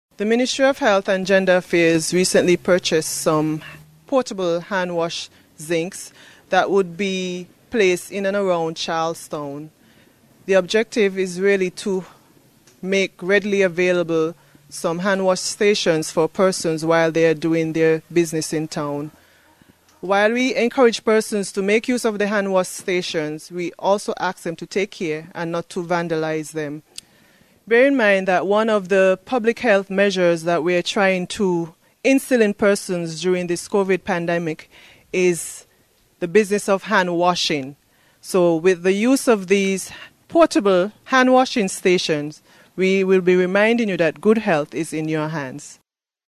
Permanent Secretary in the Ministry of Health here on Nevis, Shelisa Martin-Clarke.